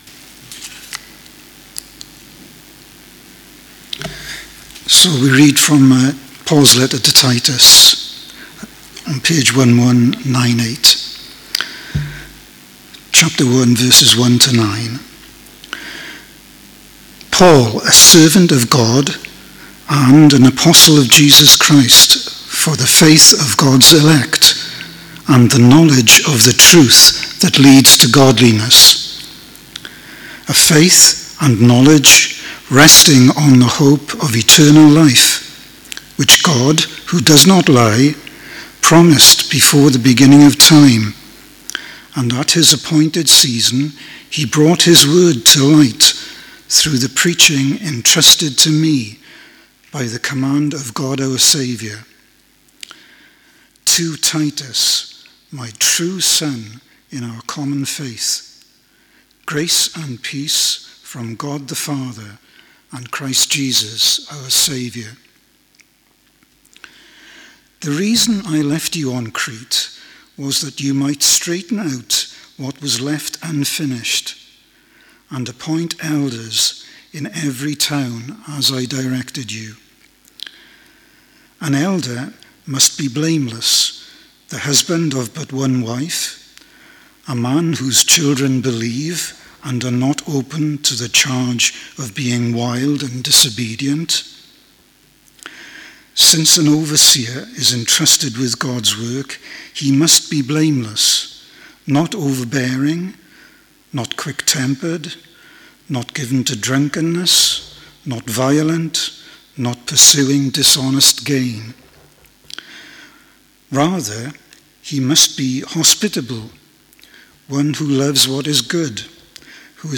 speaking as part of our Titus – Healthy Church series